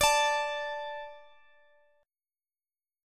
Listen to D5 strummed